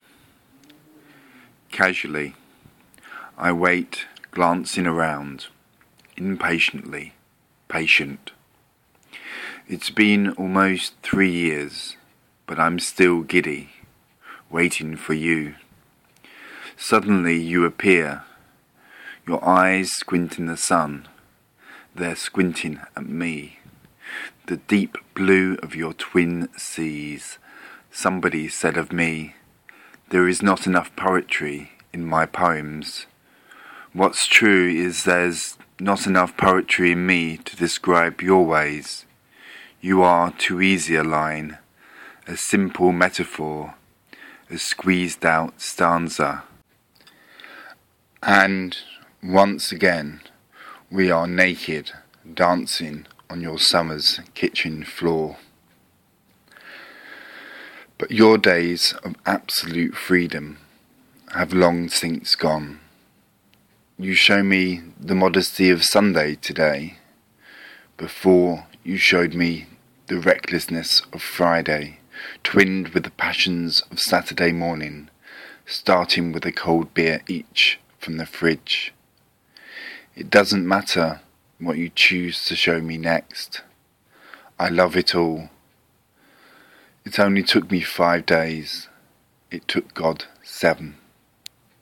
Spoken Word Poem